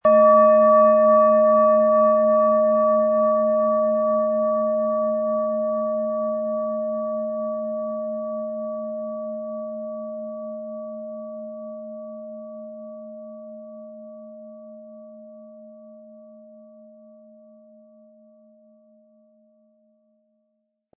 Planetenschale® Fühle Dich wohl & Männlich und Weiblich ausgleichend mit Biorhythmus Seele & Eros, Ø 16,4 cm, 600-700 Gramm inkl. Klöppel
Planetenton 1 Planetenton 2
• Mittlerer Ton: Eros
Unter dem Artikel-Bild finden Sie den Original-Klang dieser Schale im Audio-Player - Jetzt reinhören.
MaterialBronze